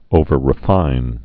(ōvər-rĭ-fīn)